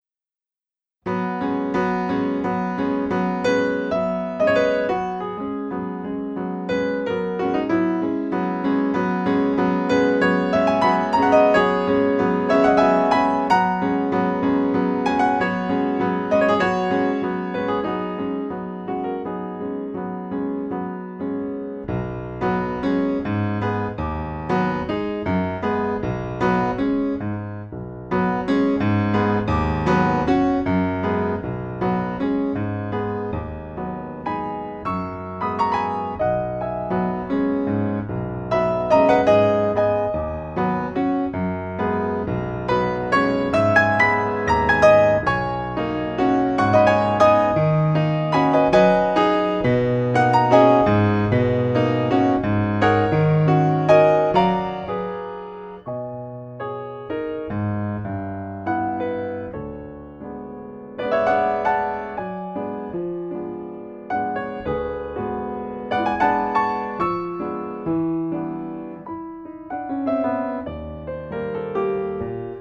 per pianoforte solo